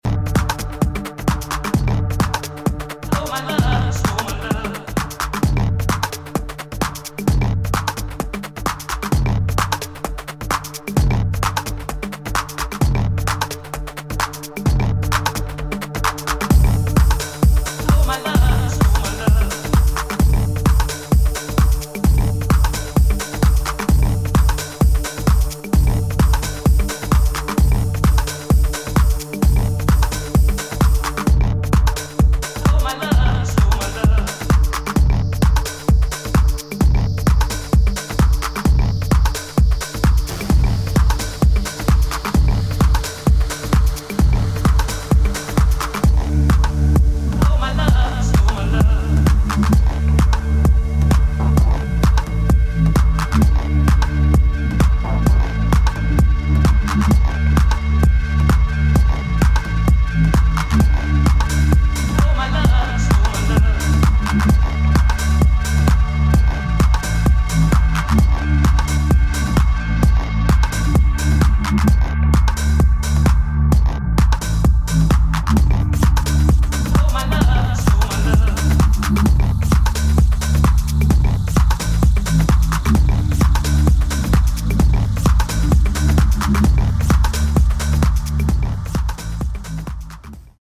[ TECHNO / MINIMAL ]